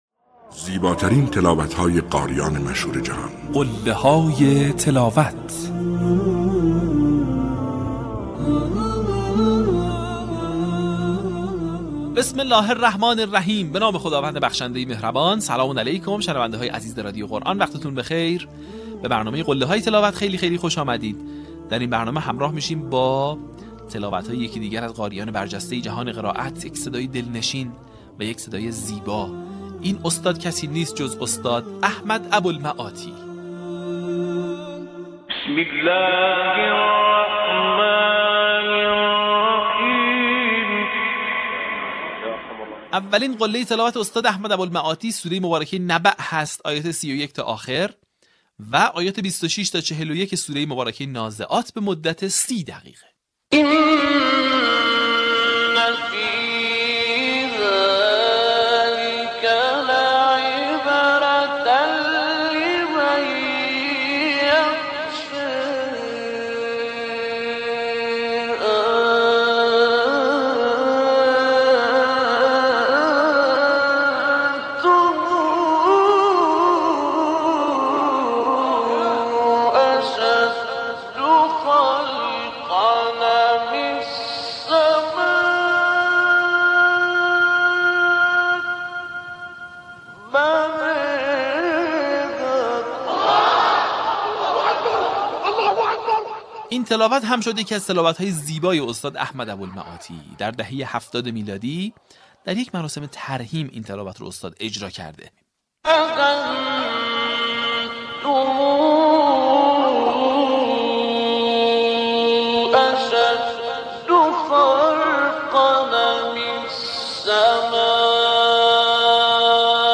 در قسمت پنجاه‌وپنجم فراز‌های شنیدنی از تلاوت‌های به‌یاد ماندنی استاد «احمد ابوالمعاطی» را می‌شنوید.
برچسب ها: احمد ابوالمعاطی ، قله های تلاوت ، فراز ماندگار ، تلاوت تقلیدی